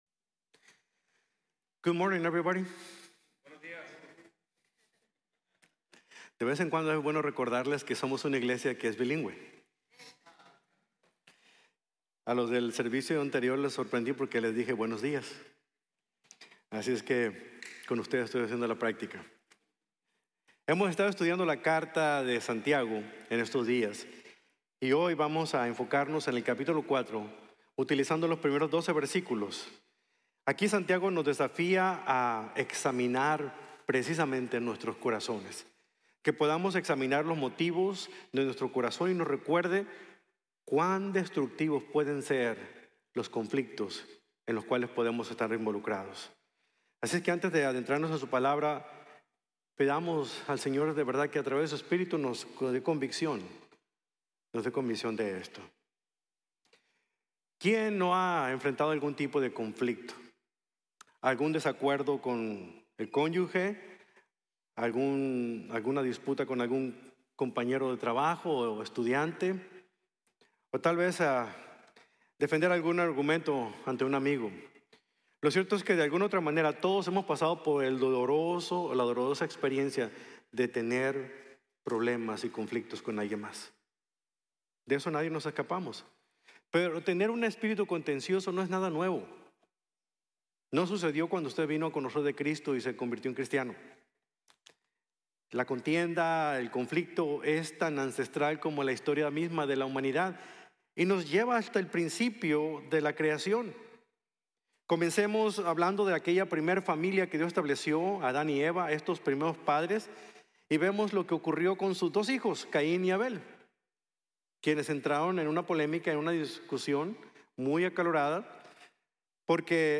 Superar los Conflictos | Sermón | Grace Bible Church